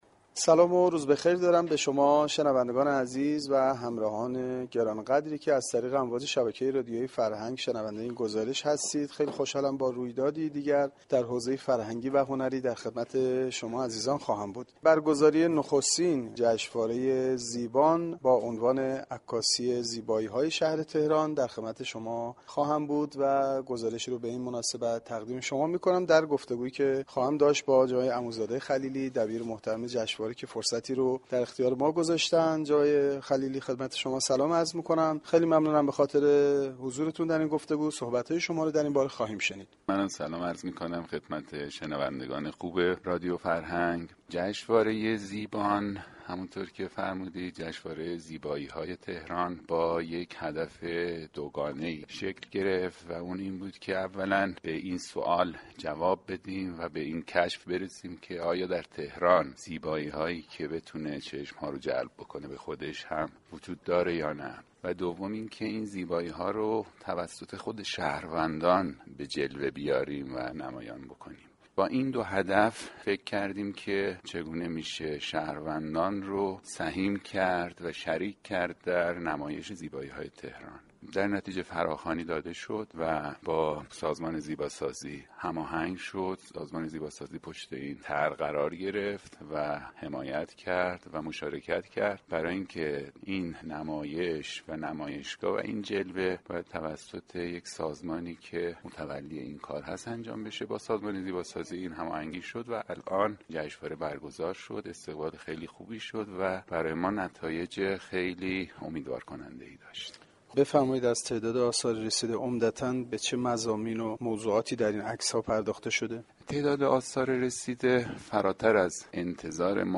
فریدون عموزاده ی خلیلی دبیر این جشنواره در گفتگو با گزارشگر رادیو فرهنگ گفت : نخستین جشنواره زیبان با هدفی دوگانه شكل گرفت .